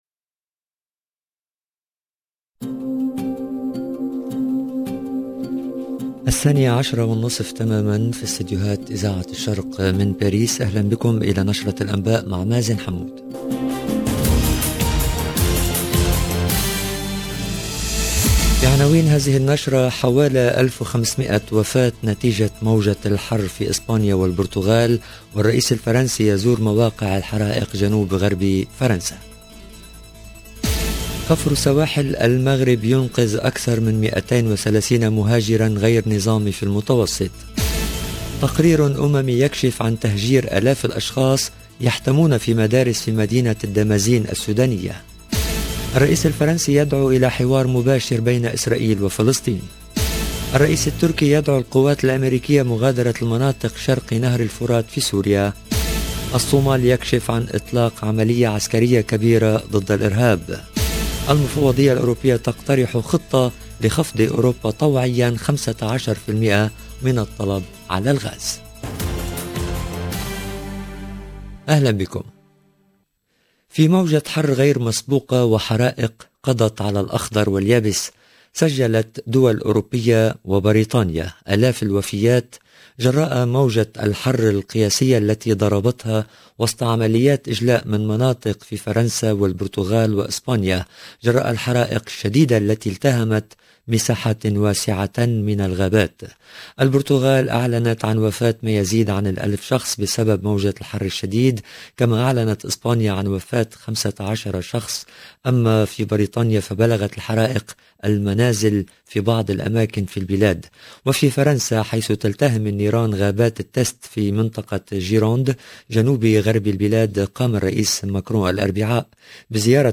LE JOURNAL EN LANGUE ARABE DE MIDI 30 DU 21/07/22